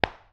冲压 " 冲压002
描述：打孔的声音。
Tag: SFX 冲头 命中 拍击